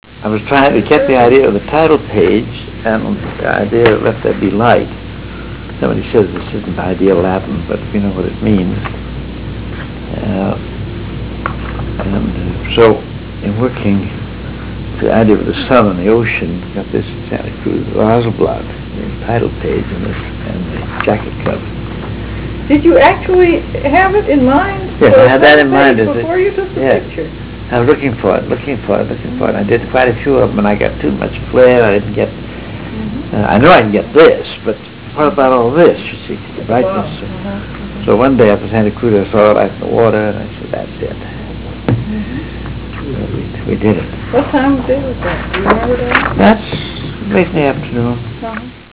399Kb Ulaw Soundf ile Hear Ansel Adams discuss this photo: [399Kb Ulaw Soundfile]